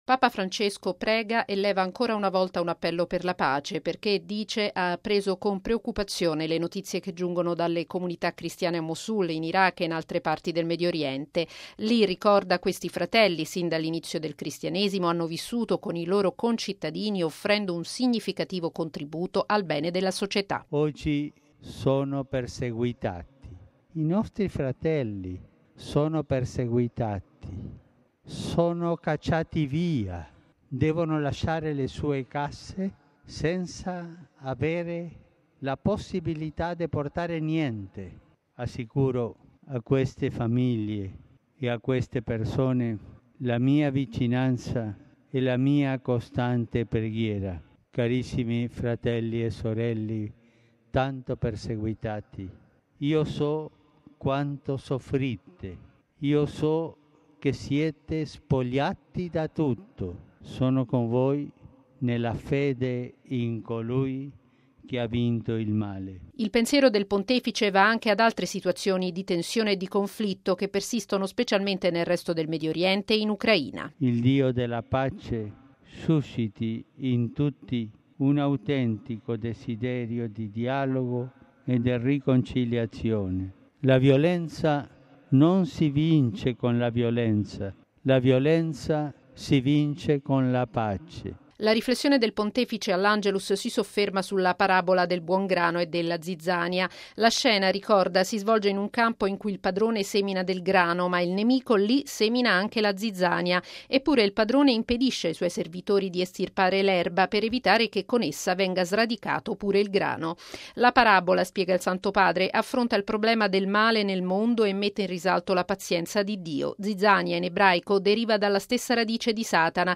Così Papa Francesco, subito dopo l’Angelus in Piazza San Pietro.